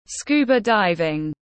Môn lặn tiếng anh gọi là scuba diving, phiên âm tiếng anh đọc là /ˈskuːbə daɪvɪŋ/
Scuba diving /ˈskuːbə daɪvɪŋ/
Scuba-diving-.mp3